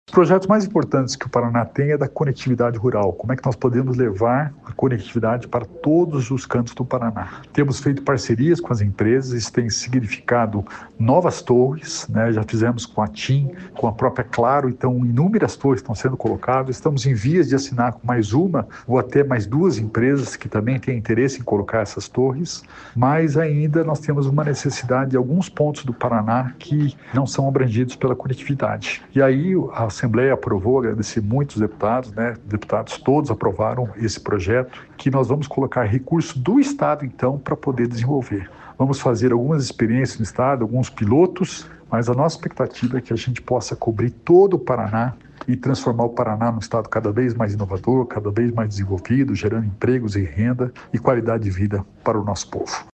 Sonora do secretário da Inovação e Inteligência Artificial, Alex Canziani, sobre a lei que prevê aumento do investimento em conectividade rural